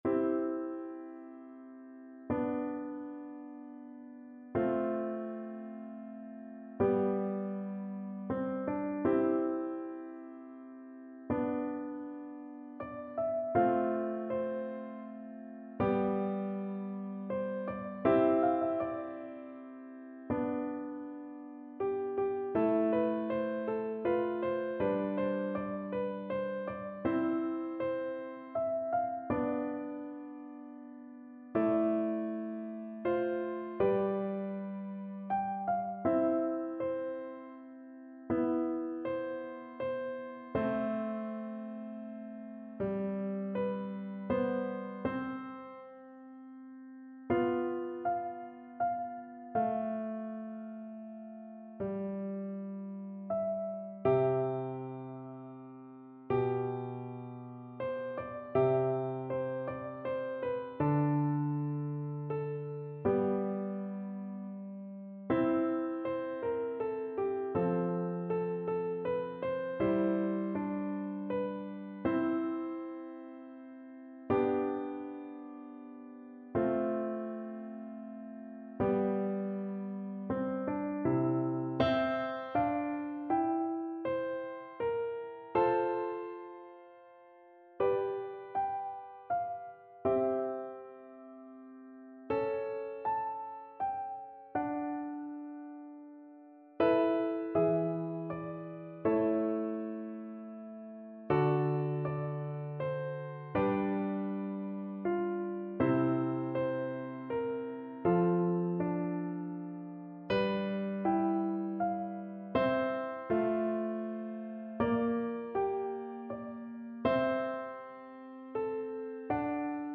Flute
C major (Sounding Pitch) (View more C major Music for Flute )
Andante
G5-A6
Classical (View more Classical Flute Music)